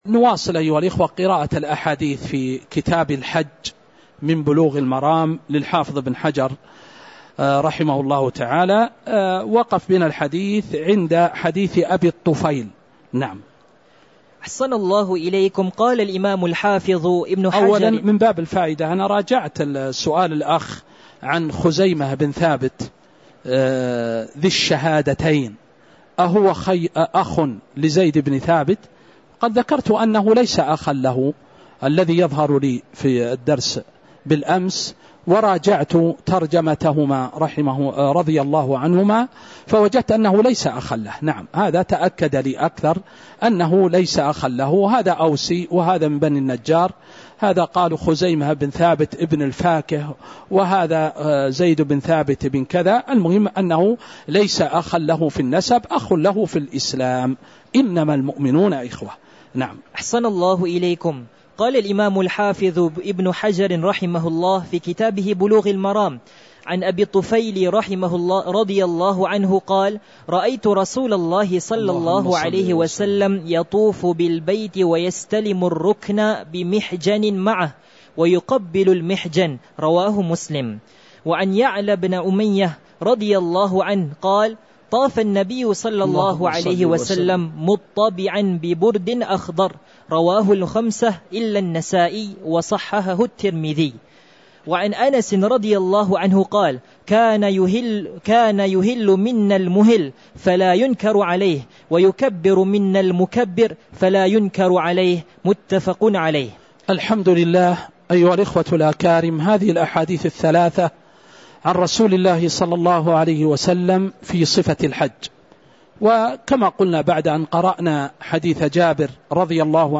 تاريخ النشر ٢٨ ذو القعدة ١٤٤٥ هـ المكان: المسجد النبوي الشيخ